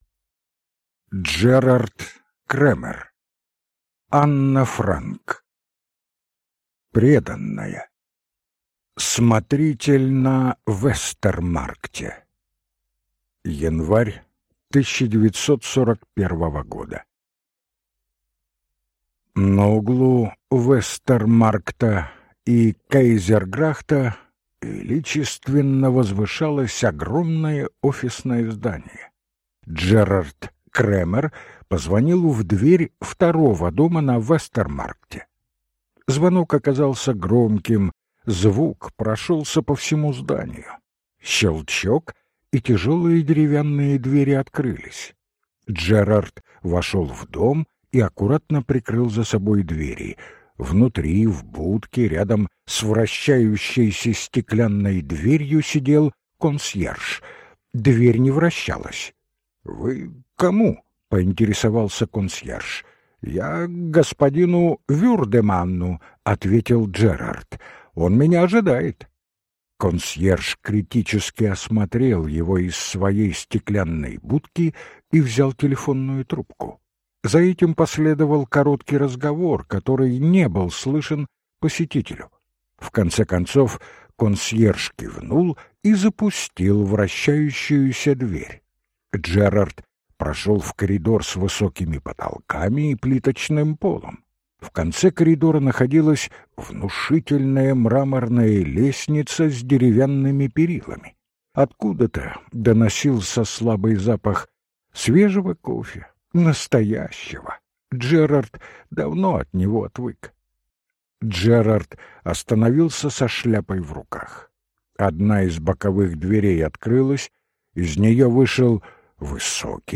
Аудиокнига Анна Франк. Преданная | Библиотека аудиокниг